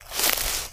High Quality Footsteps
STEPS Bush, Walk 02.wav